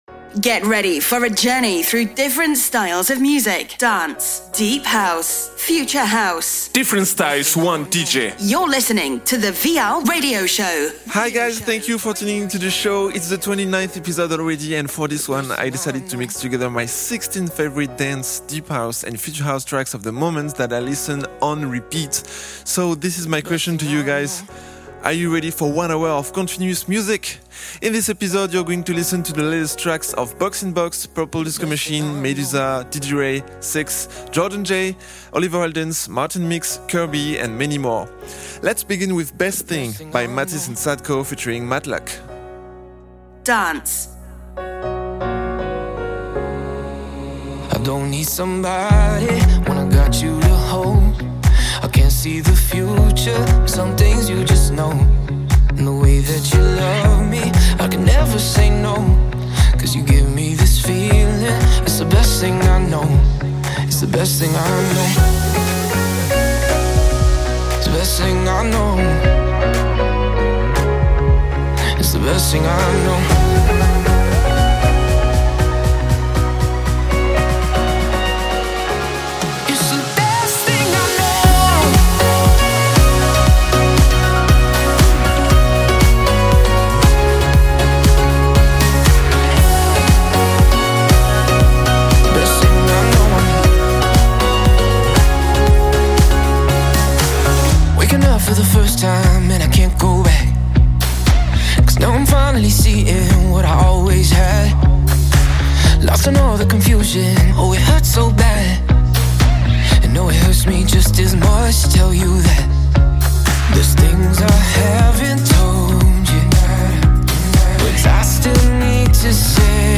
Dance, deep house & future house DJ mix.